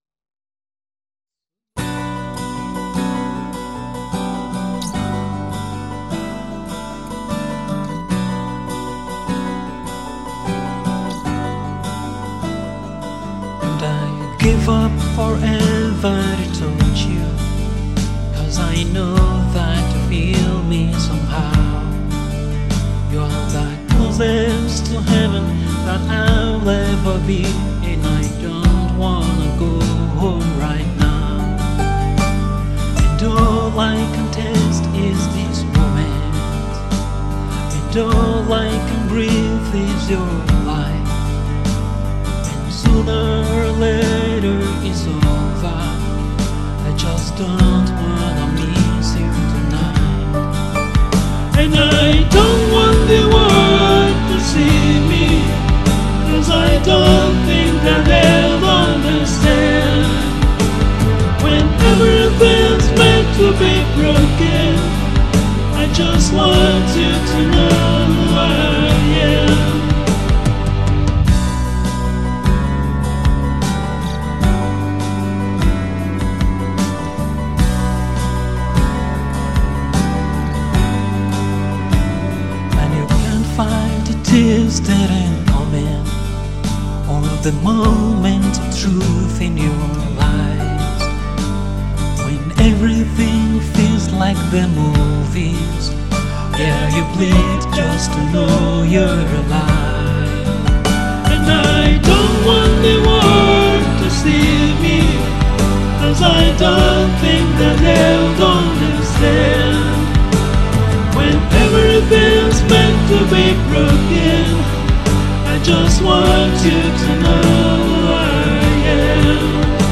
captivating acoustic cover
experience the sound of pure acoustic soul
Genre: Acoustic / Pop Rock